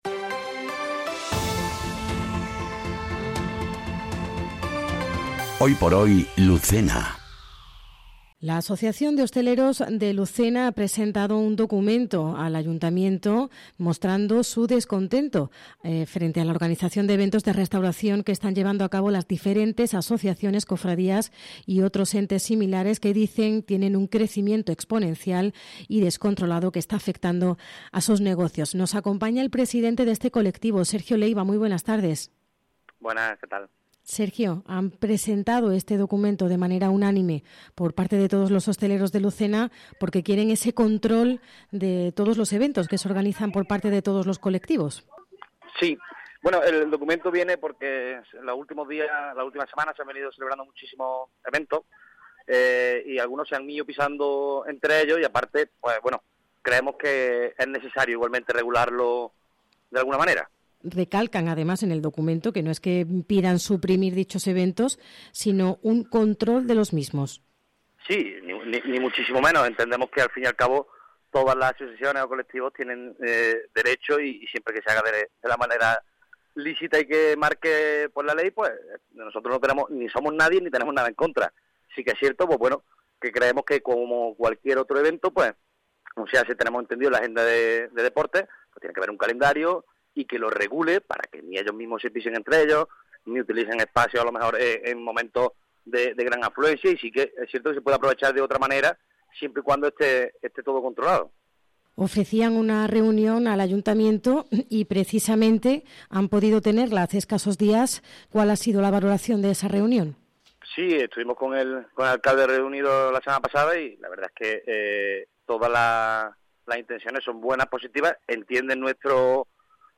ENTREVISTA | Asociación de Hosteleros de Lucena